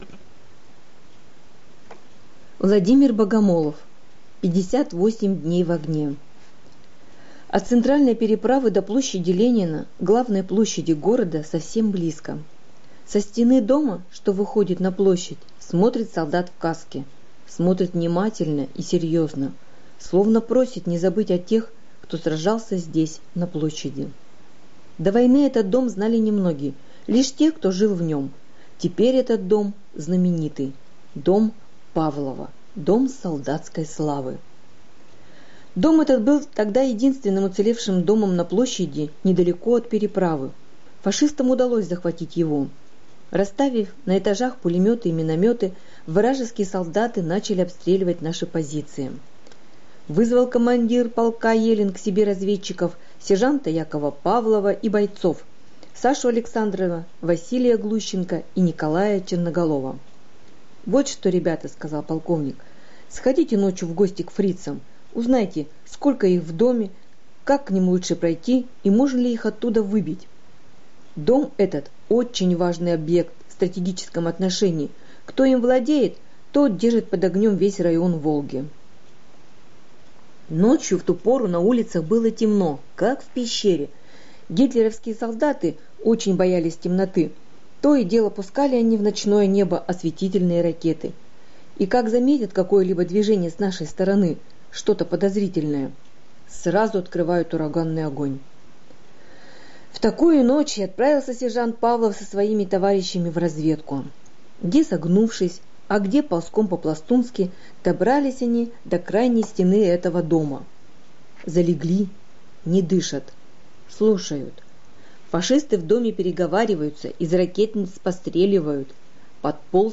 Аудиорассказ «58 дней в огне»